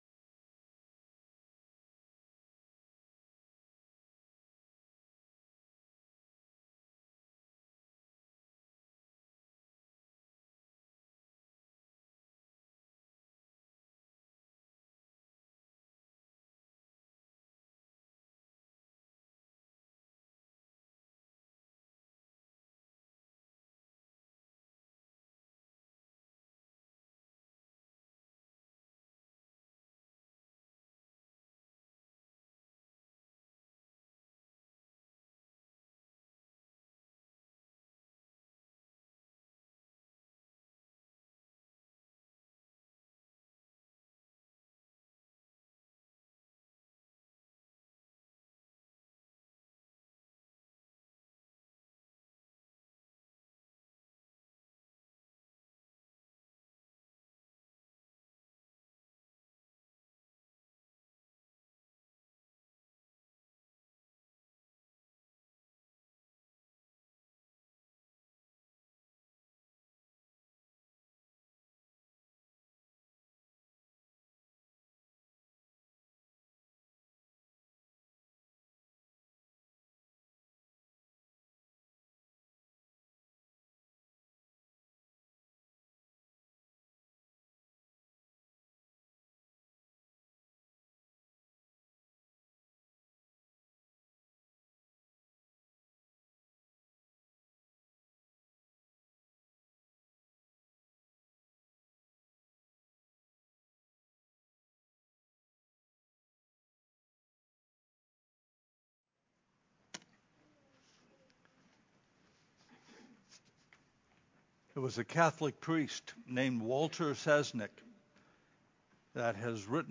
The Meaning of Freedom Sermon
The-Meaning-of-Freedom-Sermon-Audio-CD.mp3